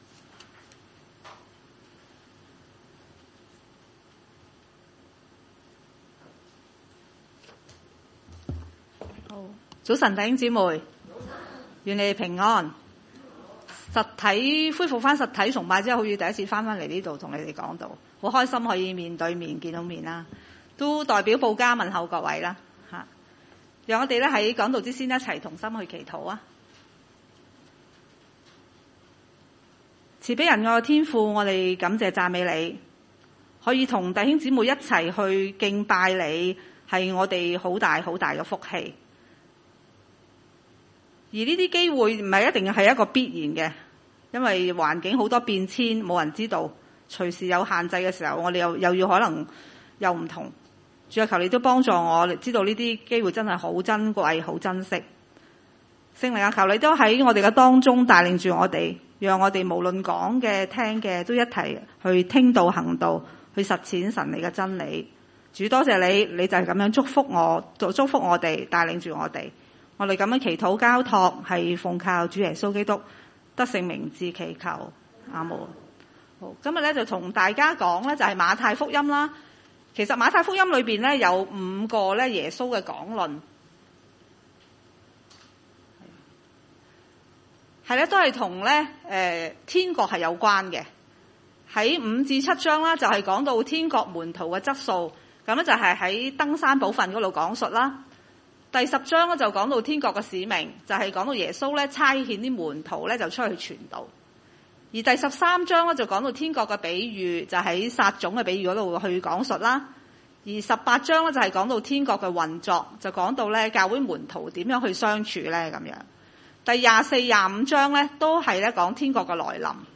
經文: 馬太福音25章14-30節 崇拜類別: 主日午堂崇拜 14.